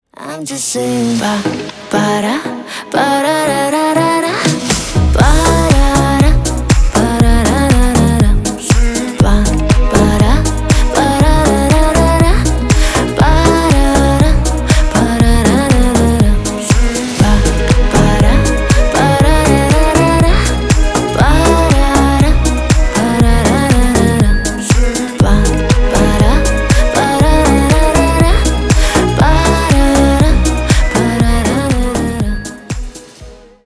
Поп Музыка # без слов
спокойные